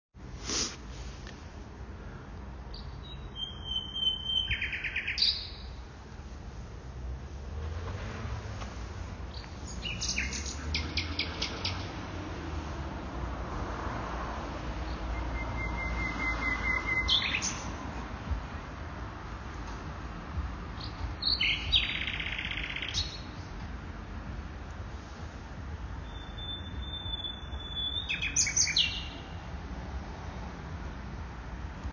Am späten Abend kann man dem Gesang einer Nachtigall vor der Villa Lützow lauschen. Im üppigen Grün des Gemeinschaftsgartens singt sie bzw. er unermüdlich zahlreiche Melodien.
Nachts am Zaun der Villa Lützow